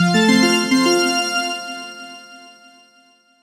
升级胜利通关音效免费下载
SFX音效